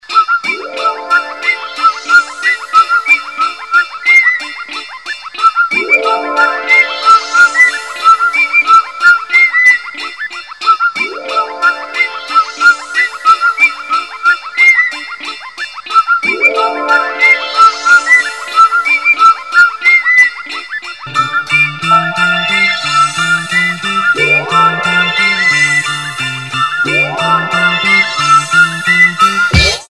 • Качество: 128, Stereo
поп
без слов
ретро